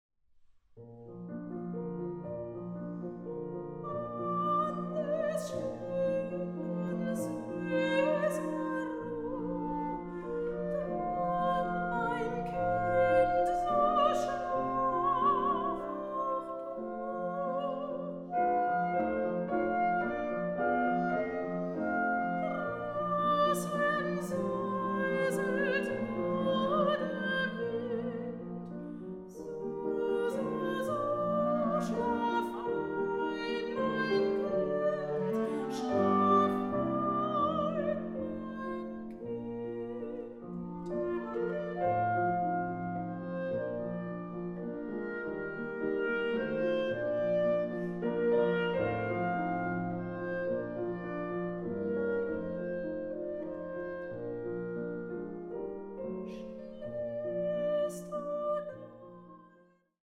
soprano
clarinet
piano